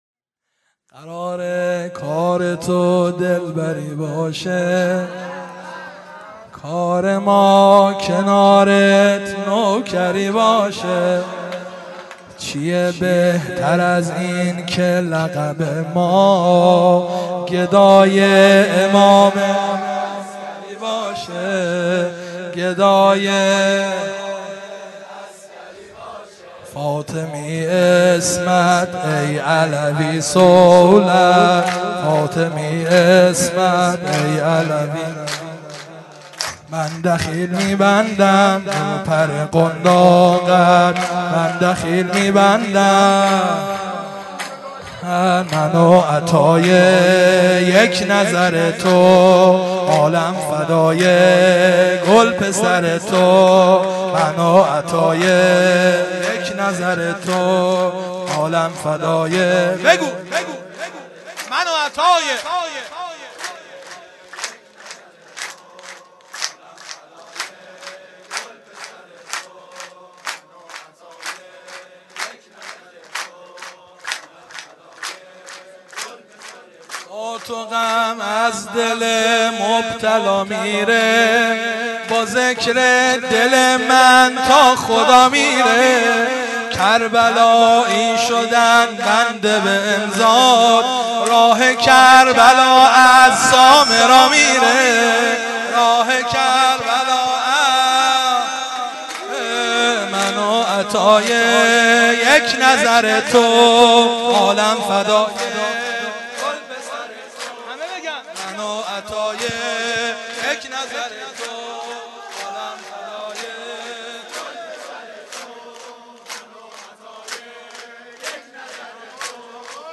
سرود - قراره کار تو دلبری باشه